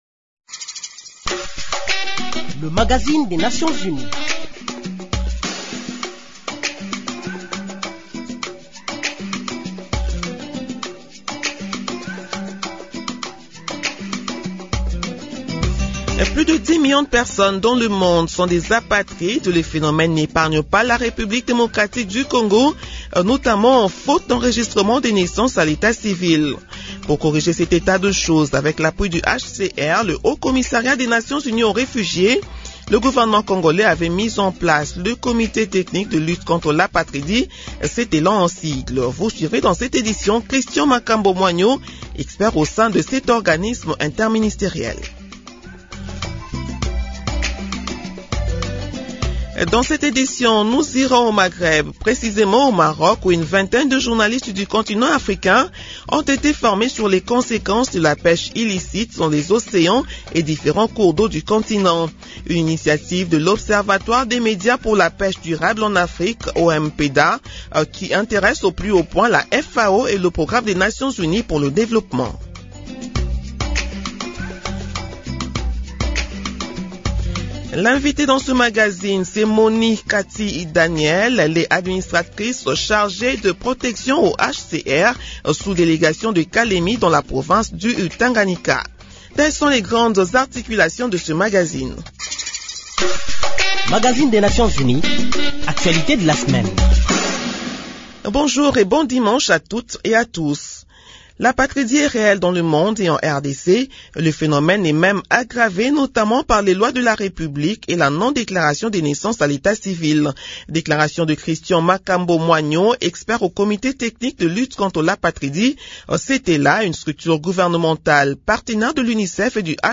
Nouvelles en bref